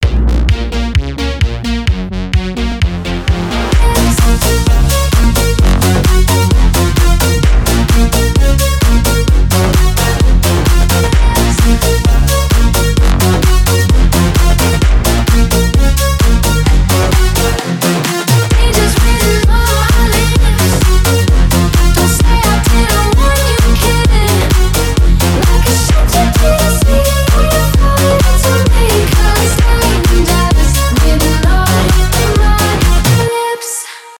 euro house
retromix
евродэнс